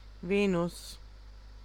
Ääntäminen
Synonyymit Hesperus Ääntäminen US UK : IPA : /ˈviː.nəs/ US : IPA : /ˈvi.nəs/ IPA : [ˈvinɪ̈s] Haettu sana löytyi näillä lähdekielillä: englanti Käännös Ääninäyte Erisnimet 1.